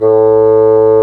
Index of /90_sSampleCDs/Roland L-CDX-03 Disk 1/WND_Bassoons/WND_Bassoon 4
WND BSSN A2.wav